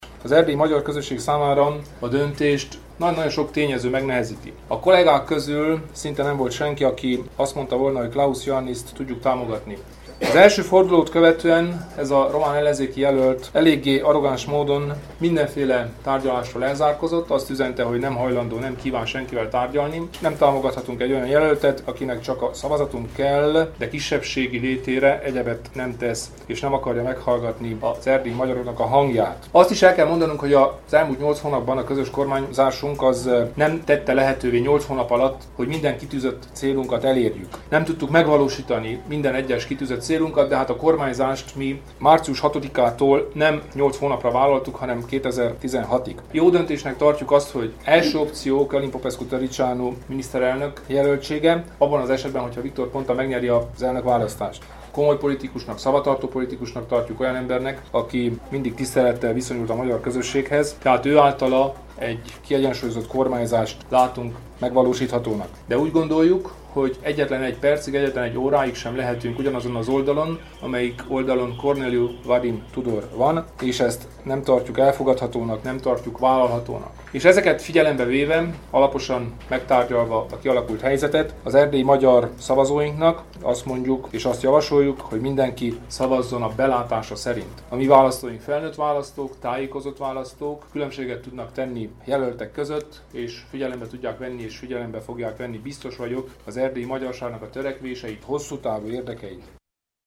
A Romániai Magyar Demokrata Szövetség Állandó Tanácsának csütörtöki ülését követően Kelemen Hunor szövetségi elnök kijelentette, hogy a román államfőválasztás második fordulójában az erdélyi magyar közösség számára több tényező nehezíti a döntést.